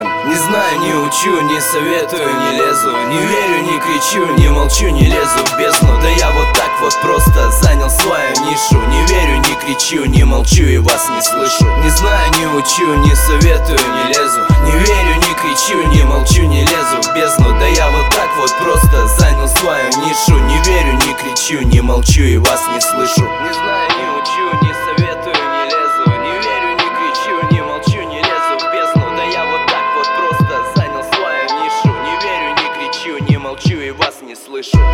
• Качество: 320, Stereo
мужской вокал
русский рэп
мотивирующие
лиричные